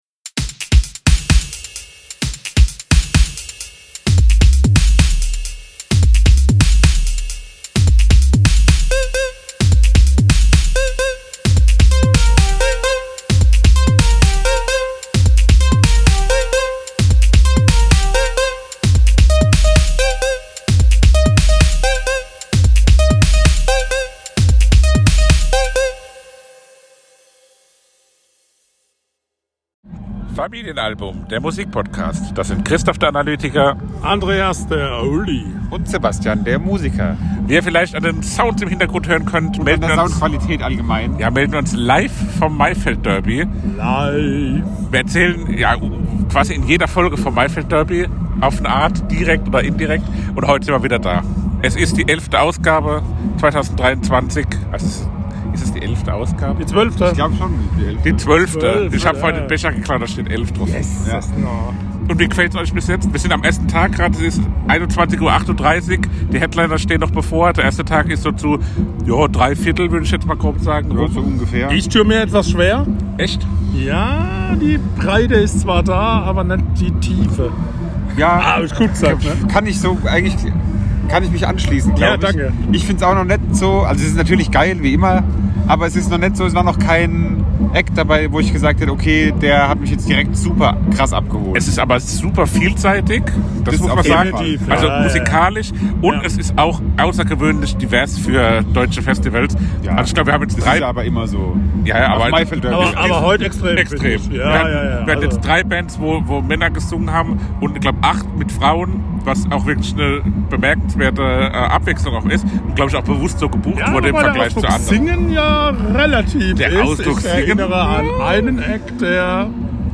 Unser Lieblings-Festival steht an und wir sind wie jedes Jahr dabei. Wir nehmen euch mit aufs Festivalgelände und sprechen über unsere Eindrücke von Tag 1!